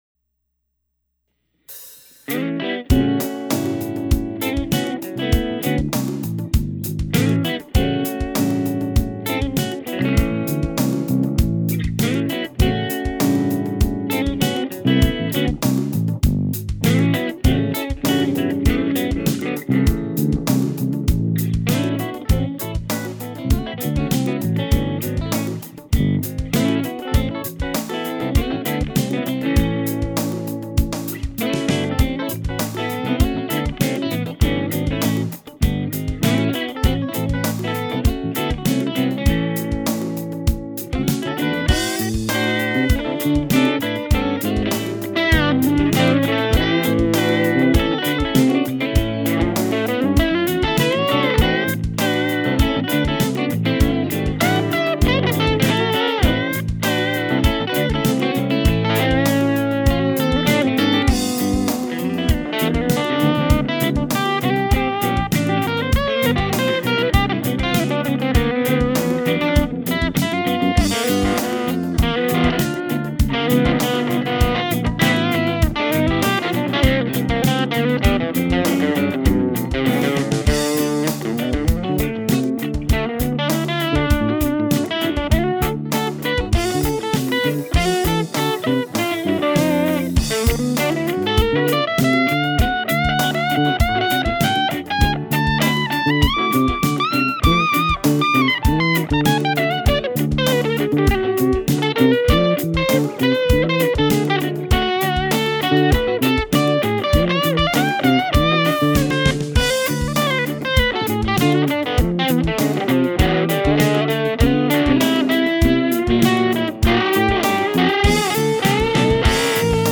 Anyways ..... it's sloppy as hell but I don't care ..... said I wuz gonna record so here it is ... man of my word and all that.
I used the PicoValve which I had messed around with the night before .... running thru an open back Emminence 10" 75watt , 16 0hm speaker with whatever mic I picked up aimed in the general direction of the speaker. (turned out to be an EV Cobalt 7) I would use the PicoValve for everything including bass guitar, and would use the same settings for everything also.
The only exceptions were that when recording bass I turned the bass down to around 7 And for the lead I turned the gain up to ... 3